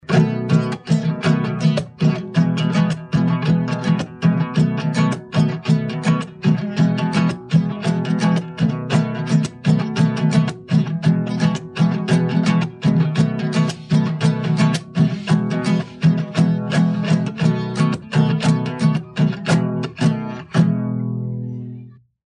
Rasqueado cuiabano
Atividade musical de caráter festivo e coreográfico composta por melodias em compasso binário composto e andamento vivo. O nome deriva da técnica de tocar ferindo-se as cordas da viola-de-cocho com as pontas dos dedos, de cima para baixo. Os instrumentos tradicionais de execução do rasqueado são a viola-de-cocho, reco-reco, mocho.
rasqueadocuiabano.mp3